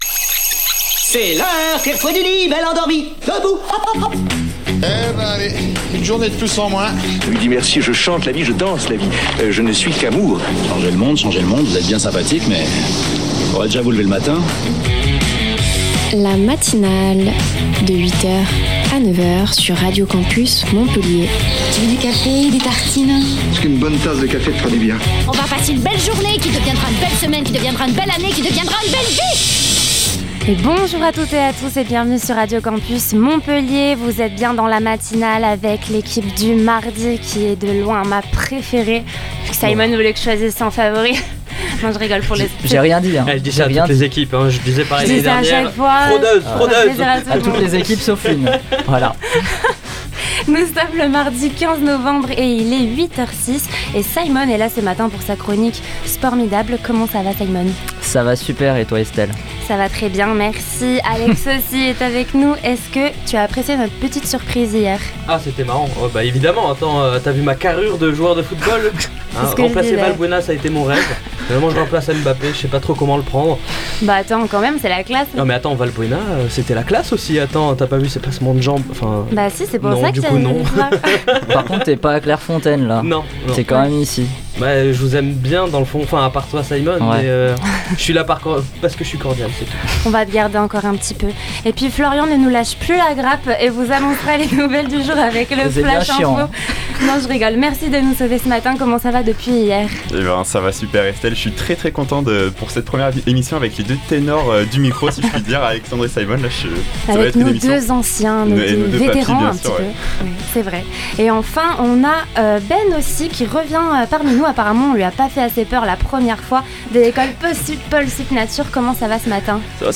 Informations, interviews, chroniques et musique, le tout accompagné d'une bonne odeur de café chaud.